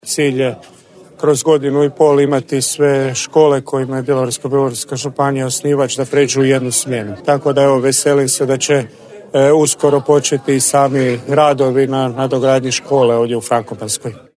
Evo što je o nadogradnji škole u Frankopanskoj za Radio Daruvar izjavio župan Marko Marušić